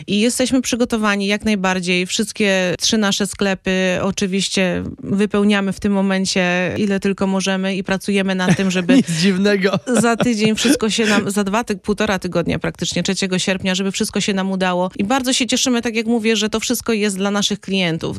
Gość Radio Deon zapowiada, że sklep zapewni ochronę i pomoc służb porządkowych, spodziewając się wielu chętnych, aby nie doszło do niebezpiecznych incydentów.